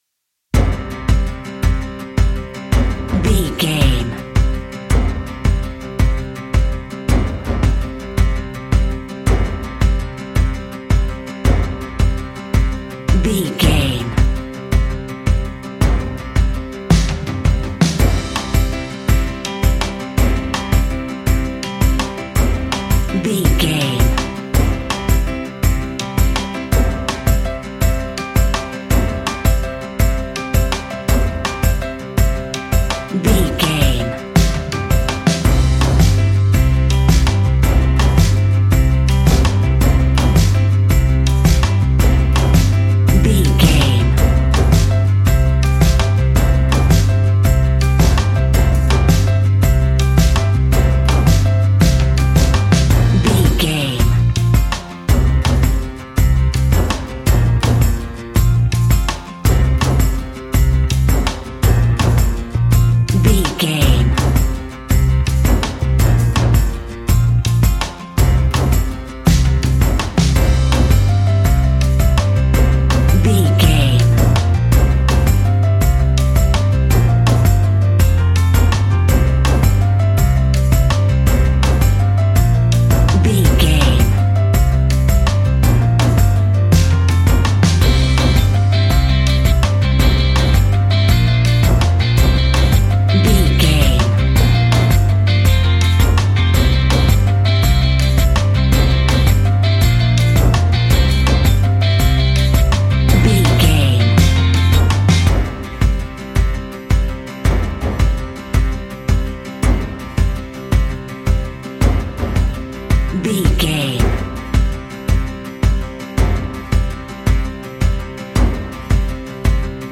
Mixolydian
folk instrumentals
acoustic guitar
mandolin
drums
double bass
accordion